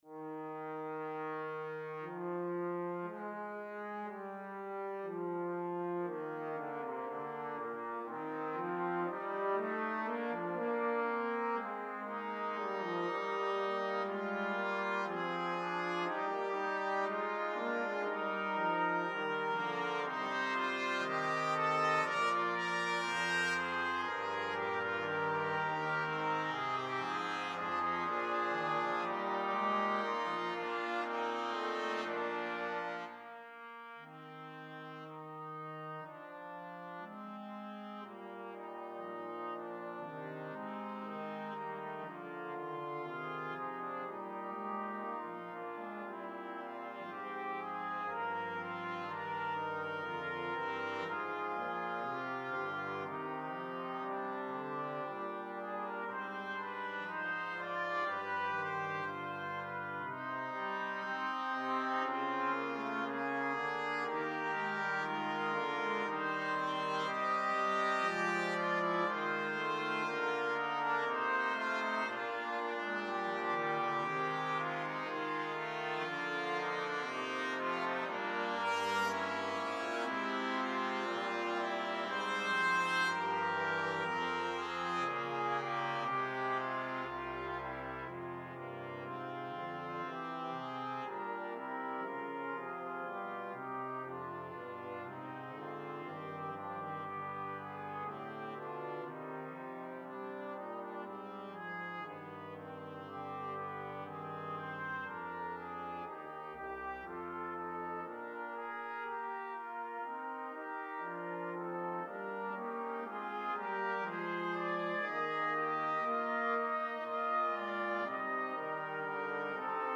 Besetzung: Brass Quartet (2Trp, 2Trb)